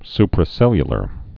su·pra·cel·lu·lar
(sprə-sĕlyə-lər)